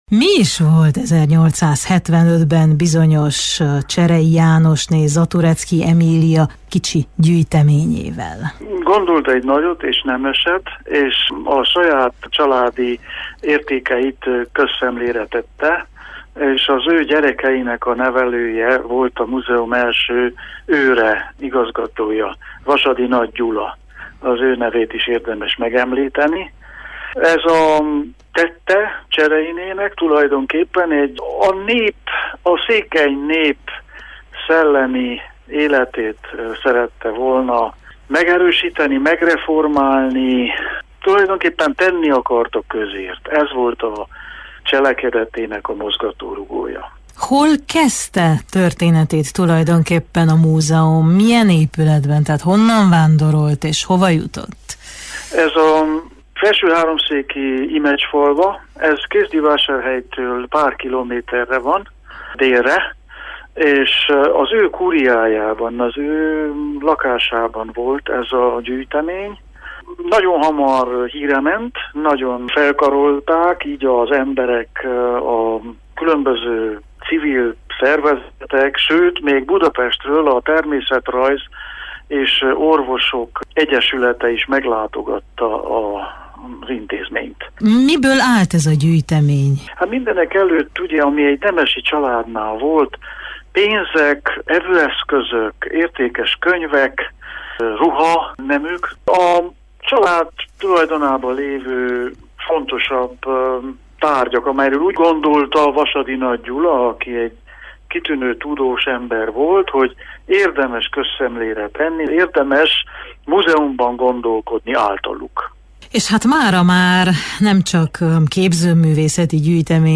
beszélget a 150 éves évfordulóról és ünnepségekről